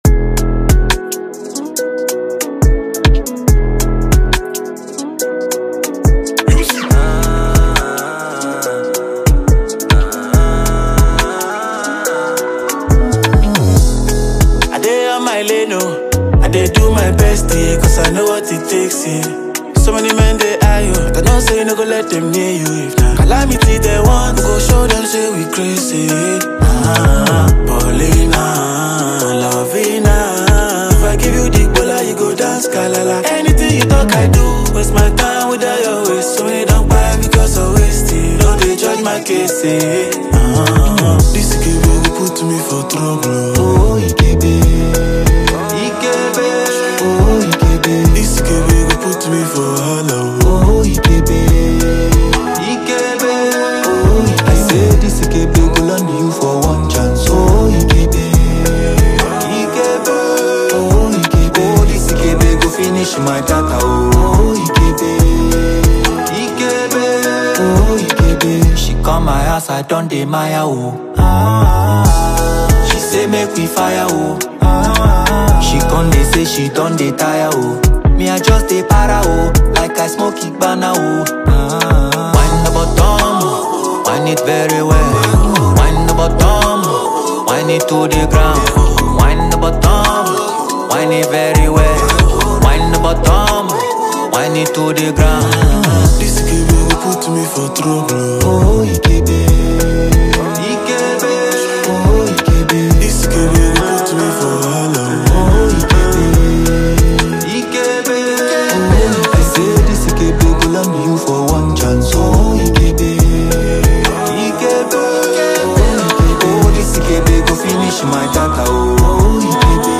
Talented Nigerian Afrobeats singer